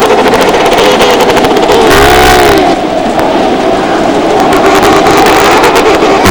See Movie (for better quality, ask me) Audios Want to hear penguins' voices?
emperor.wav